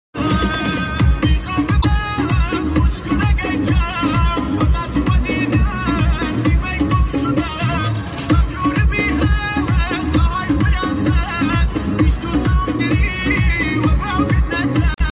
ترانه کردی